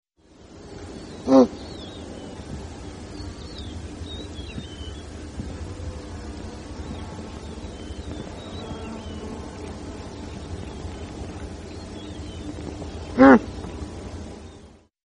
Звуки газели животного еще вариант с газелью Гранта